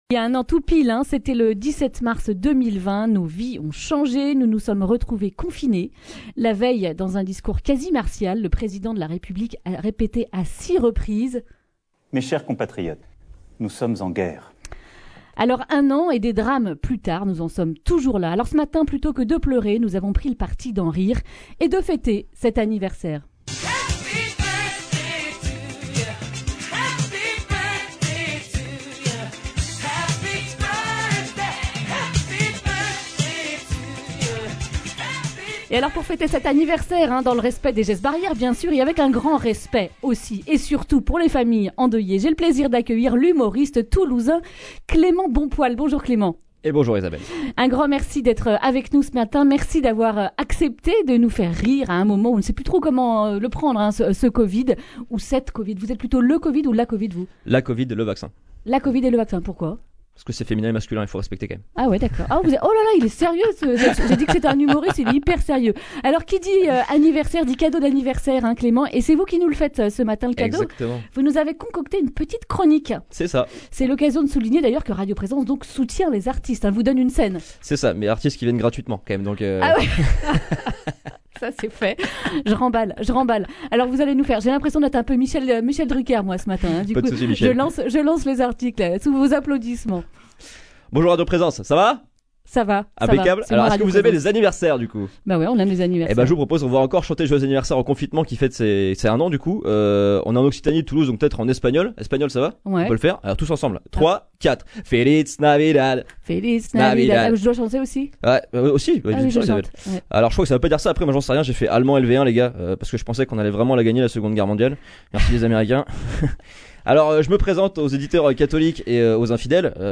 Accueil \ Emissions \ Information \ Régionale \ Le grand entretien \ Confinement : un anniversaire au poil !